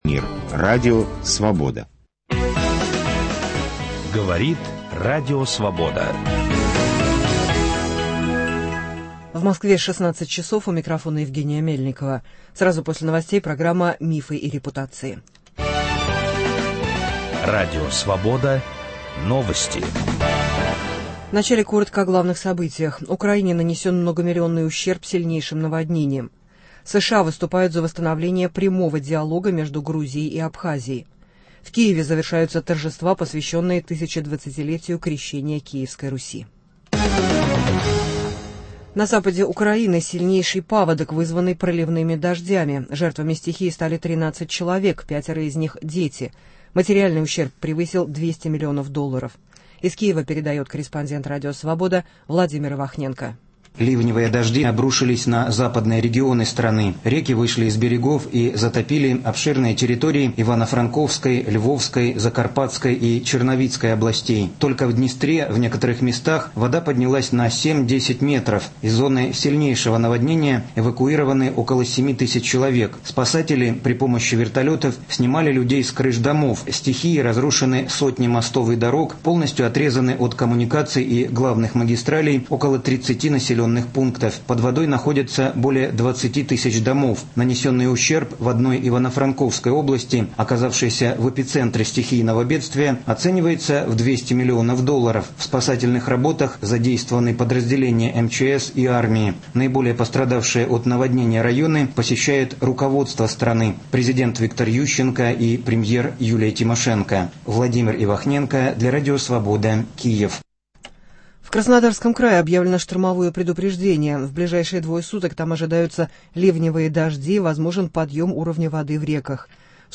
Звучат фрагменты выступлений писателя разных лет.